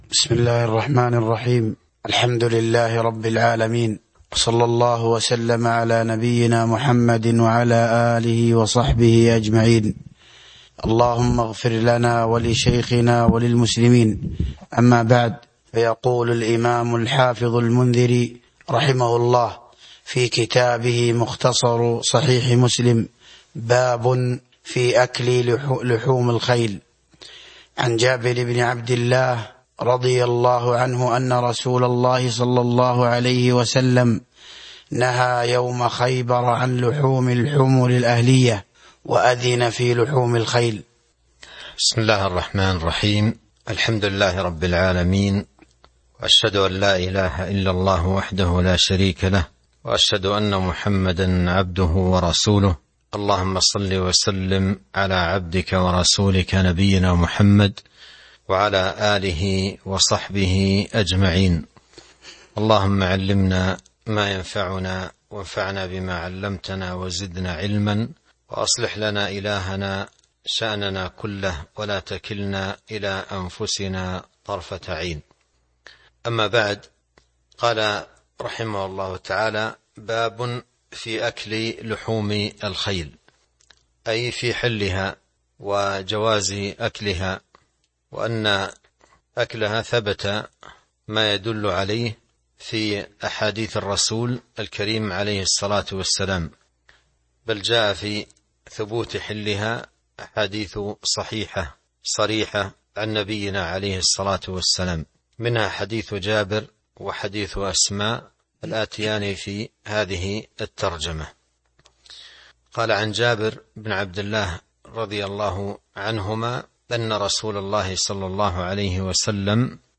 تاريخ النشر ٢٧ جمادى الآخرة ١٤٤٣ هـ المكان: المسجد النبوي الشيخ